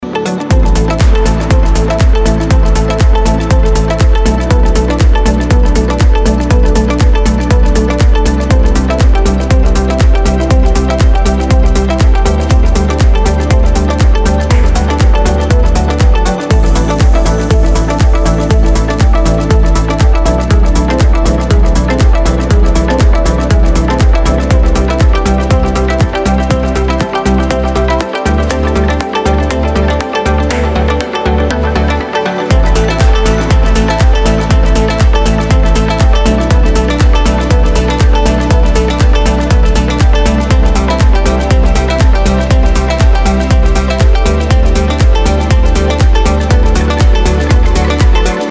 • Качество: 320, Stereo
deep house
спокойные
без слов
nu disco
Indie Dance